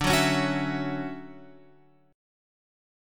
D Major 9th